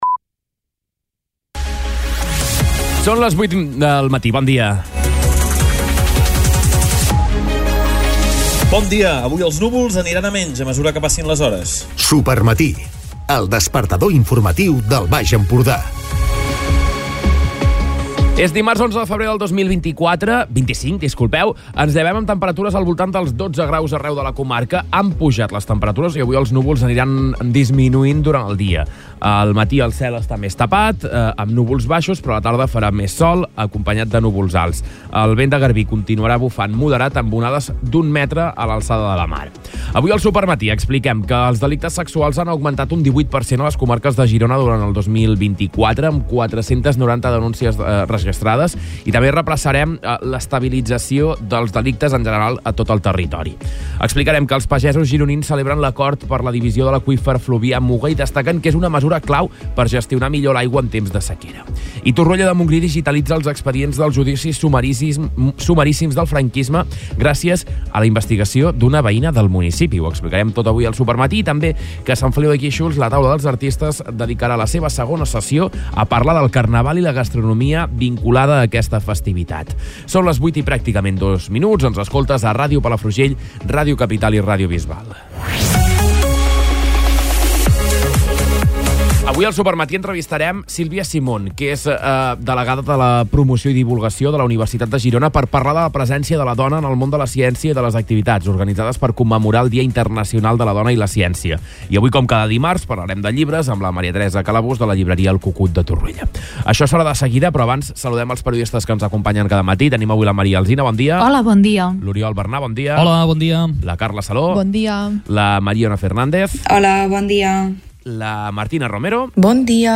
Escolta l'informatiu d'aquest dimarts